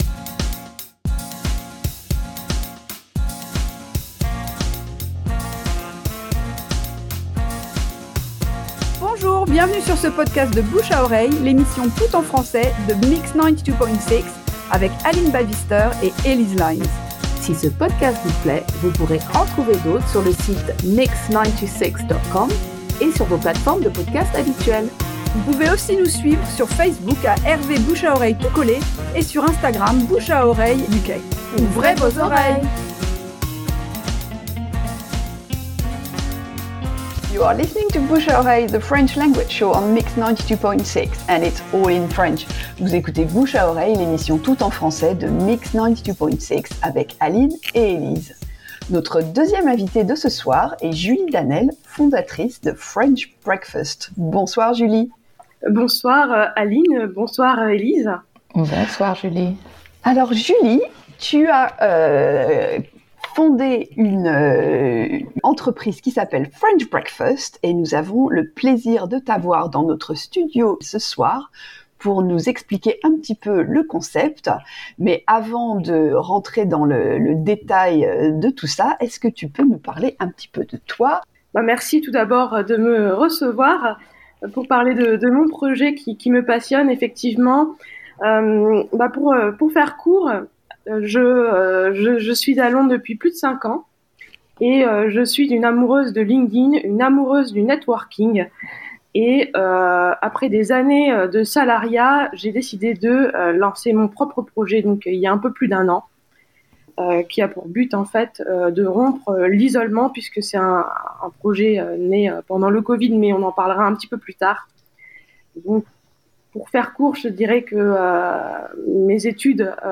Extrait d’une émission diffusée sur Mix 92.6 en Juin 2023.